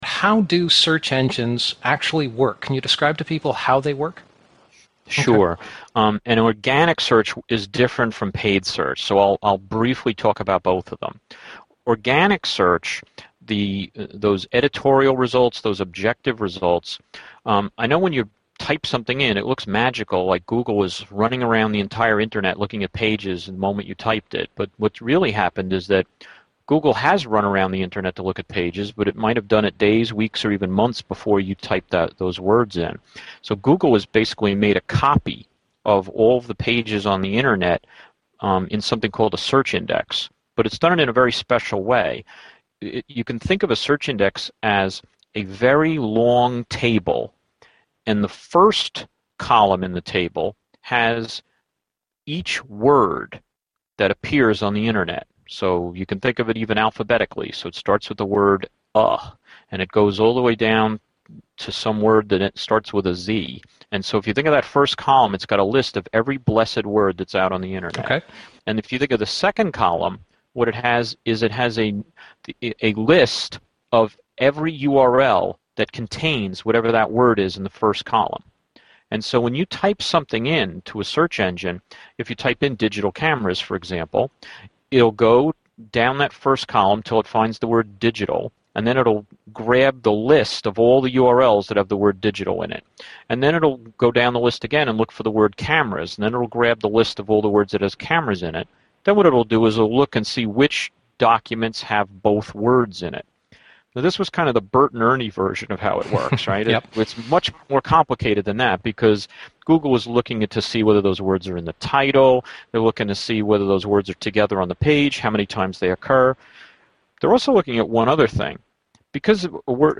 He describes both organic search , based on page content and link frequency, and paid advertising search. This is an excerpt from a longer interview on his "how to" book on search engine marketing.